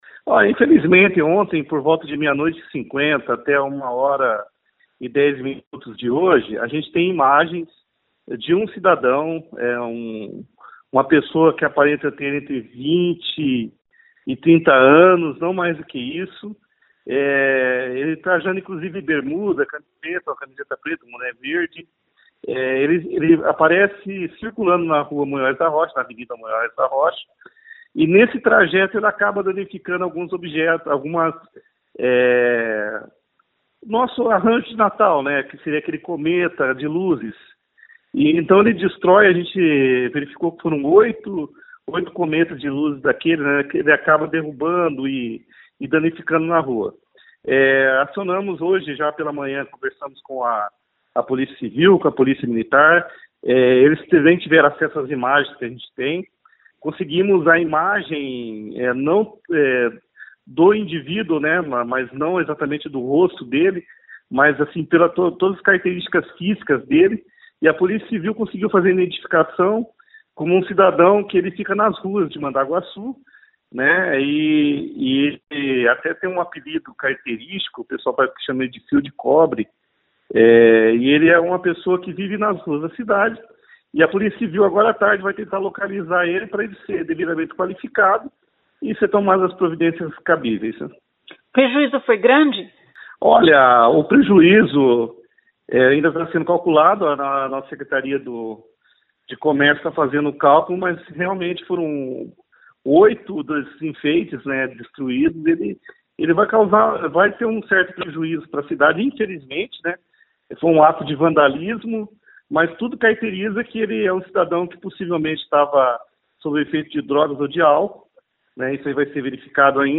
Ouça o que diz o secretário: